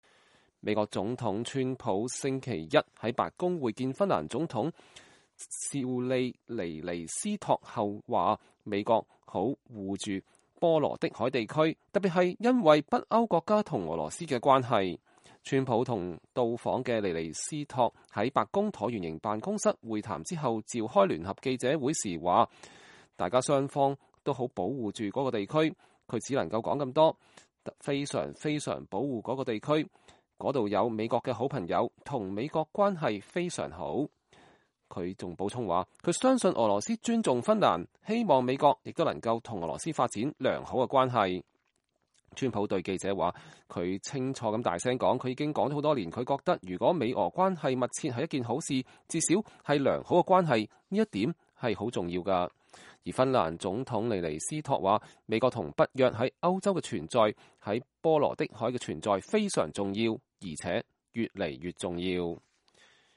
川普與來訪的芬蘭總統尼尼斯托在白宮召開聯合記者會 (2017年8月28日)